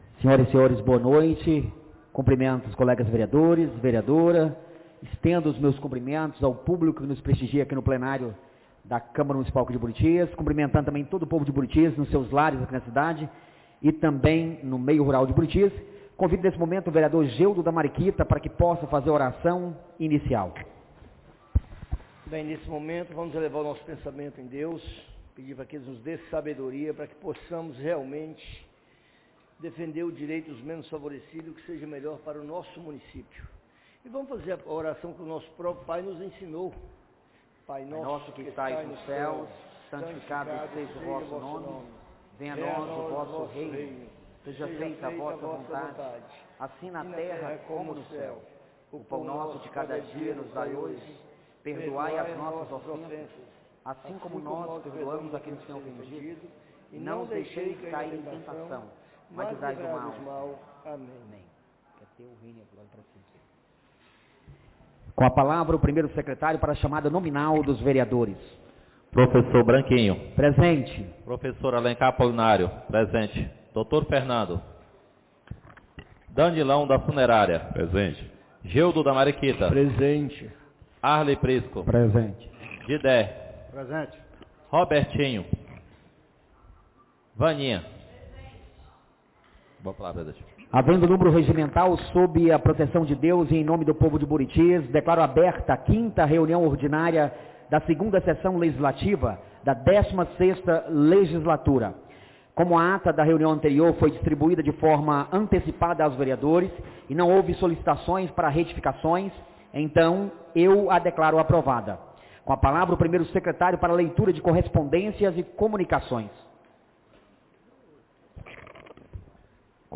5ª Reunião Ordinária da 2ª Sessão Legislativa da 16ª Legislatura - 02-03-26